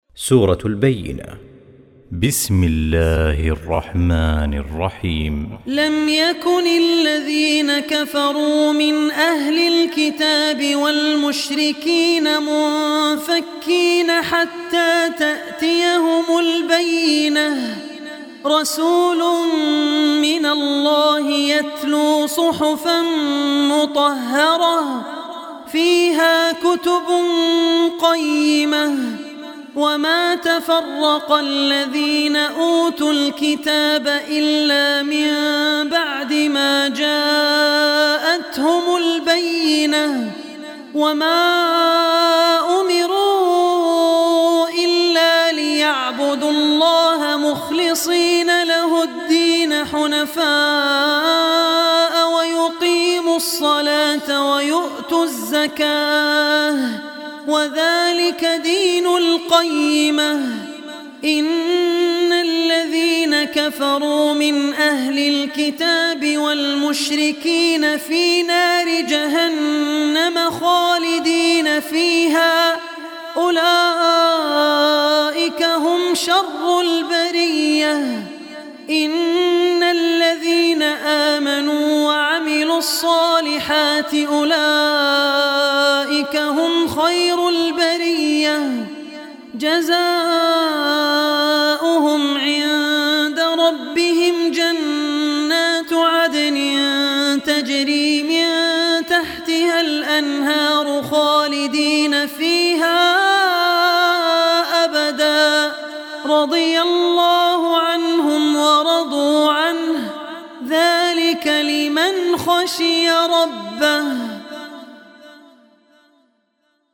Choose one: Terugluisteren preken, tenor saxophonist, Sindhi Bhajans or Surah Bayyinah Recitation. Surah Bayyinah Recitation